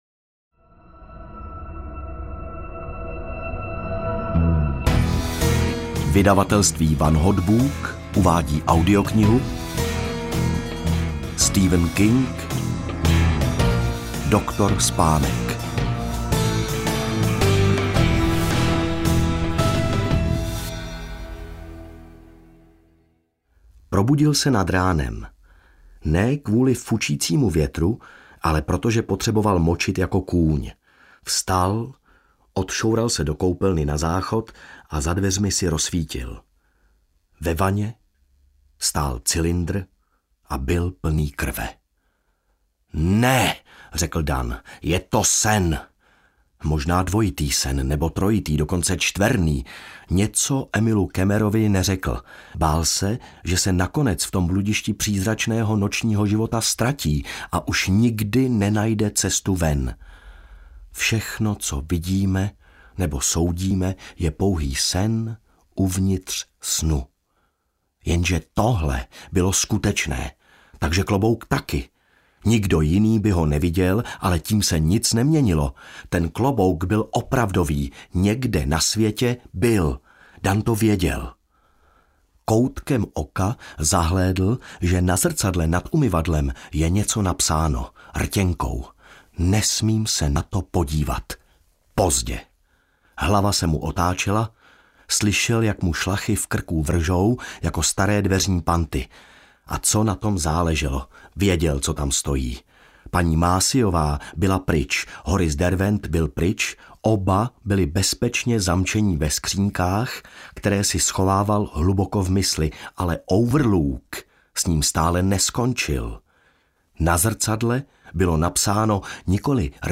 Ukázka z knihy
doktor-spanek-audiokniha